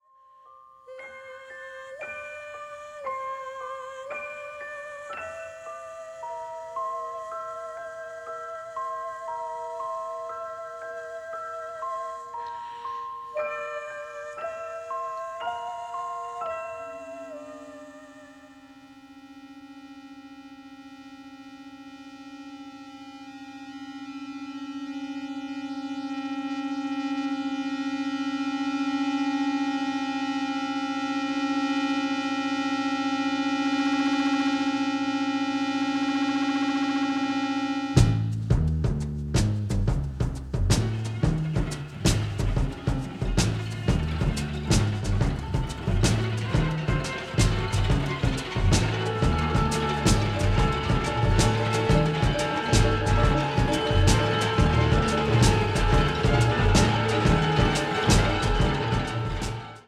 sophisticated avant-garde sound